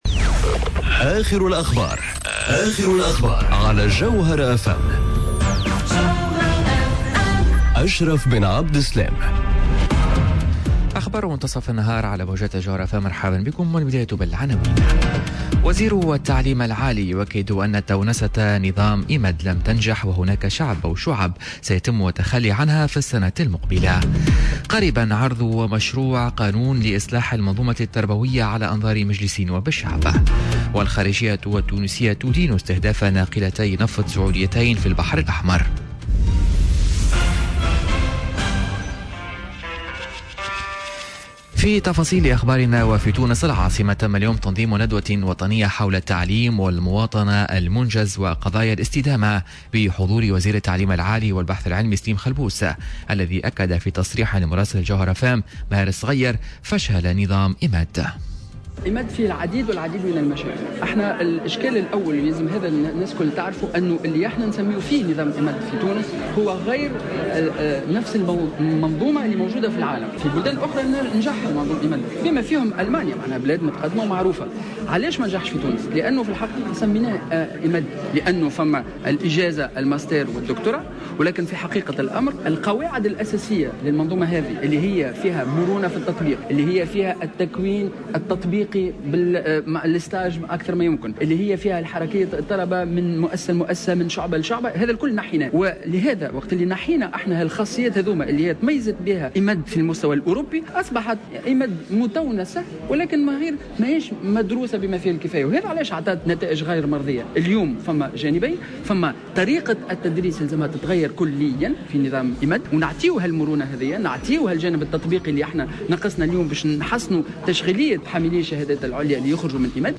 نشرة أخبار منتصف النهار ليوم الثلاثاء 31 جويلية 2018